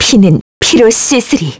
Lewsia_B-Vox_Skill1_kr.wav